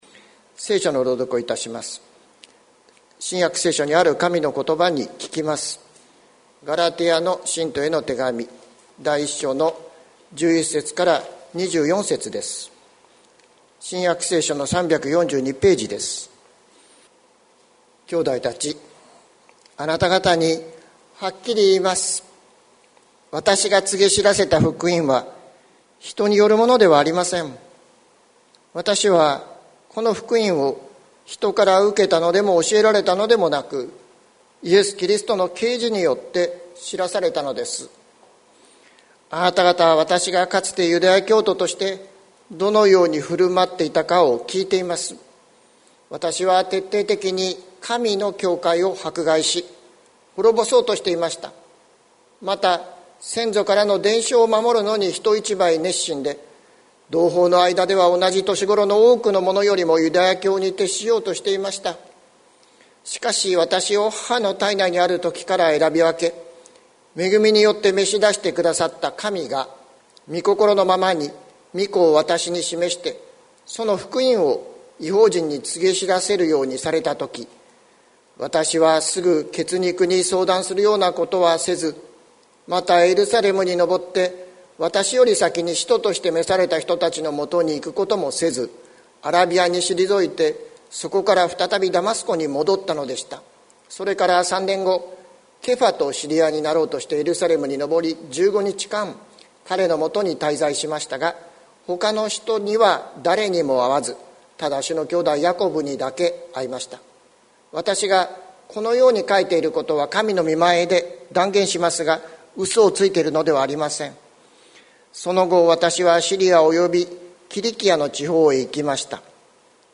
2021年04月25日朝の礼拝「キリスト者とは何者か」関キリスト教会
説教アーカイブ。